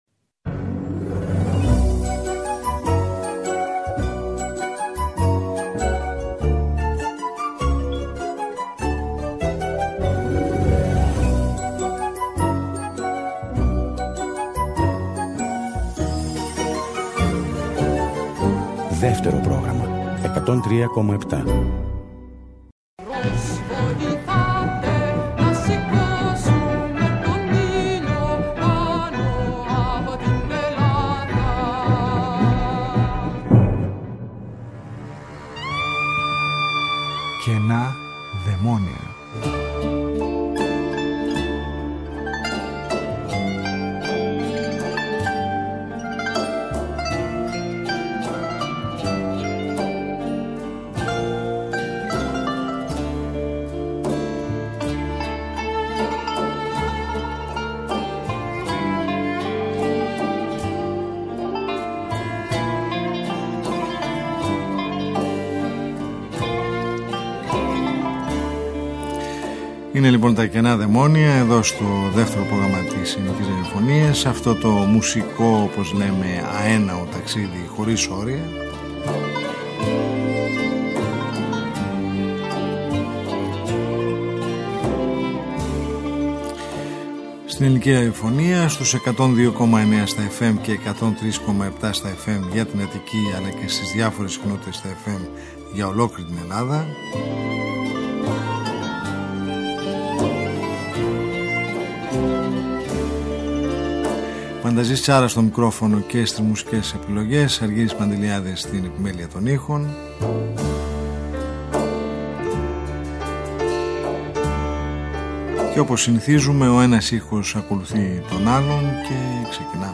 Μια ραδιοφωνική συνάντηση κάθε Σάββατο στις 22:00 που μας οδηγεί μέσα από τους ήχους της ελληνικής δισκογραφίας του χθες και του σήμερα σε ένα αέναο μουσικό ταξίδι.